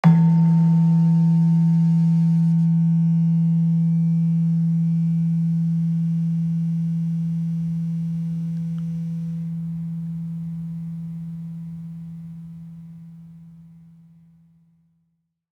Gamelan Sound Bank
Gender-1-E2-f.wav